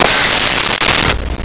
Glass Bottle Break 07
Glass Bottle Break 07.wav